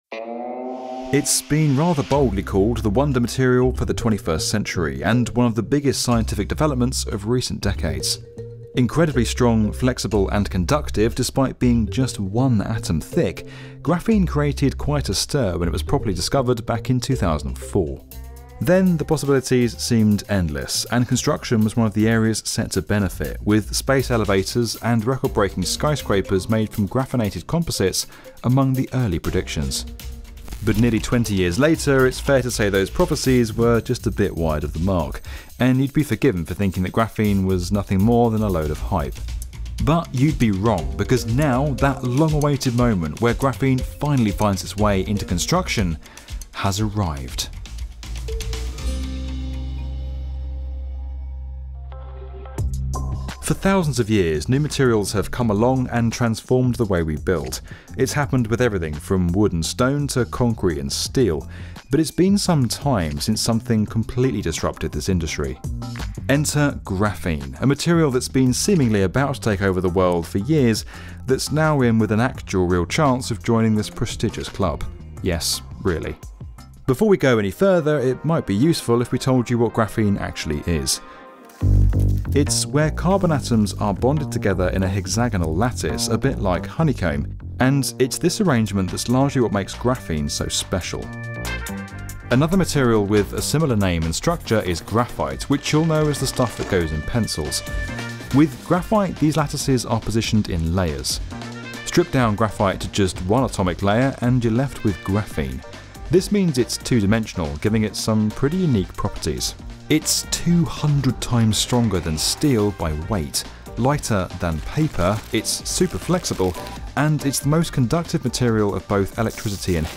Executive Producer and Narrator